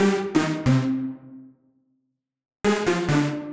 GA_SupiSyn136C-01.wav